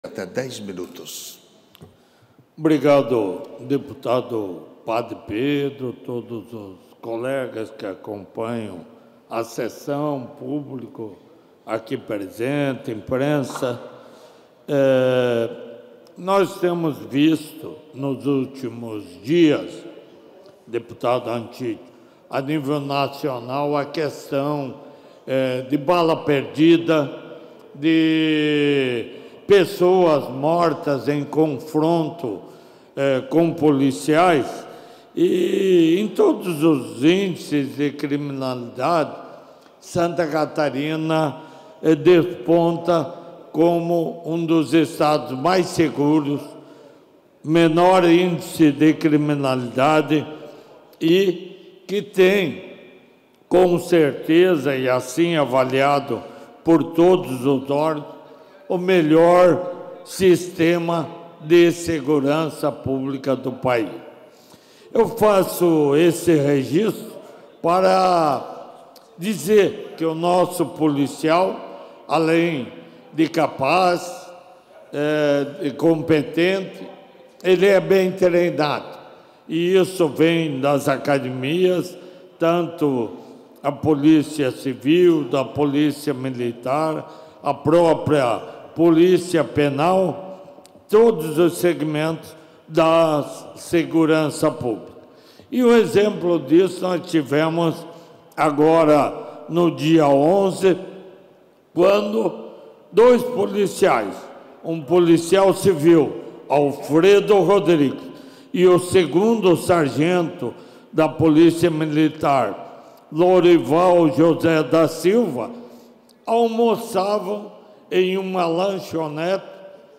Pronunciamentos da sessão ordinária desta terça-feira (15)
Confira os pronunciamentos dos deputados na sessão ordinária desta terça-feira (15):